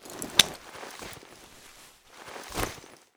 medkit.ogg